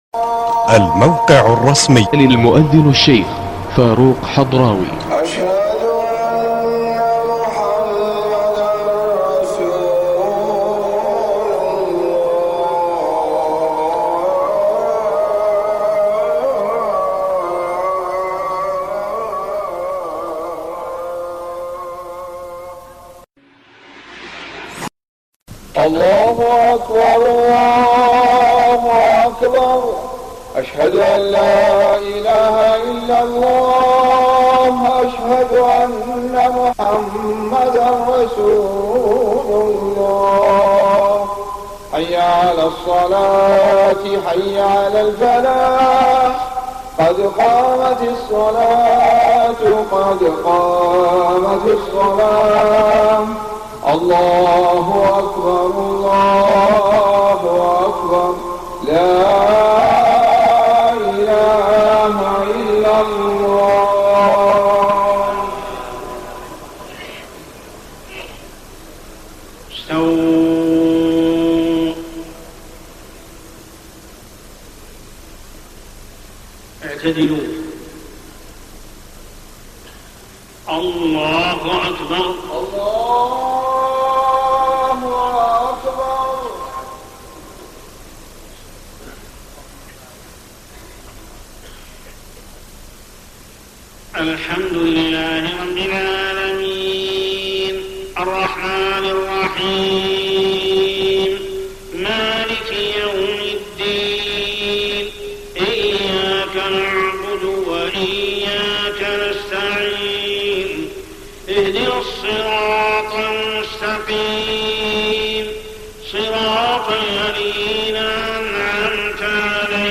صلاة العشاء 17 رمضان 1423هـ سورتي الضحى و الشرح > 1423 🕋 > الفروض - تلاوات الحرمين